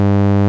kõrihääle spekter põhitooniga 100 Hz.
k6rihaale_spekter_synt.wav